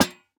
Minecraft Version Minecraft Version latest Latest Release | Latest Snapshot latest / assets / minecraft / sounds / block / lantern / break2.ogg Compare With Compare With Latest Release | Latest Snapshot
break2.ogg